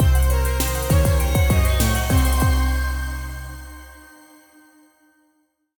ghost_train_load_01.ogg